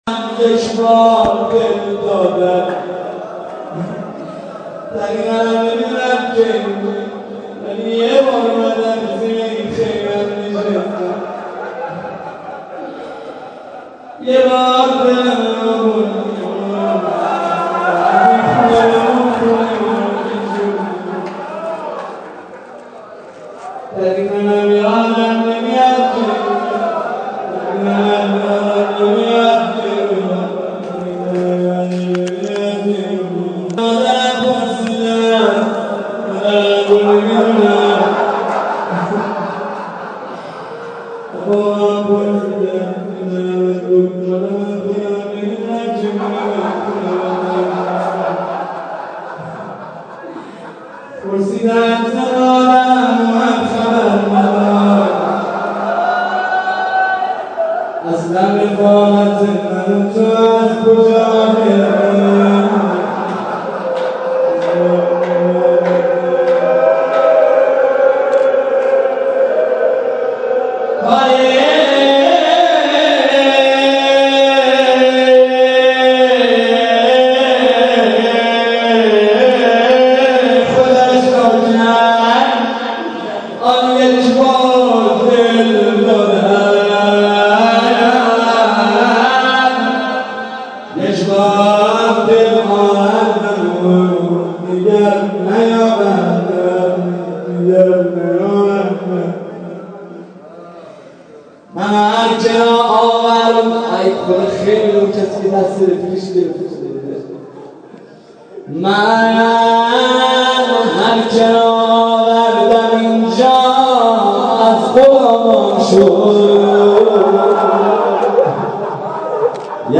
صوت روضه
مراسم روضه هفتگی در هیئت یازهرا